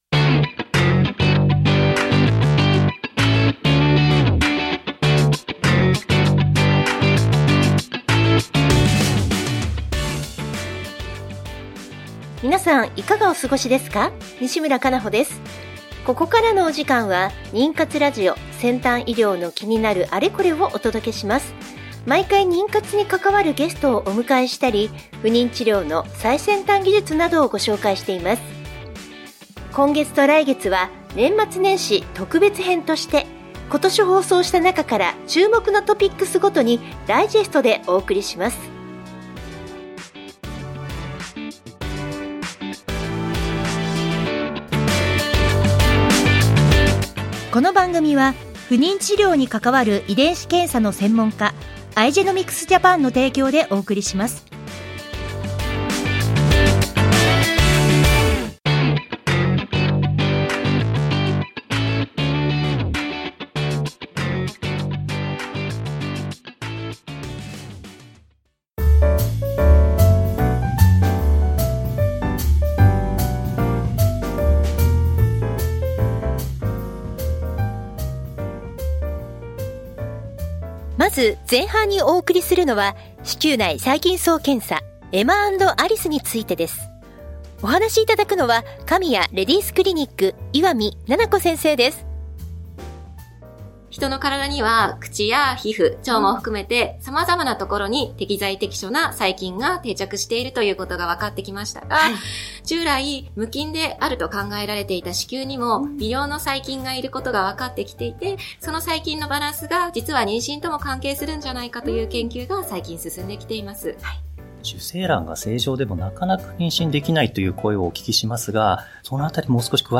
ナビゲーターはフリーアナウンサー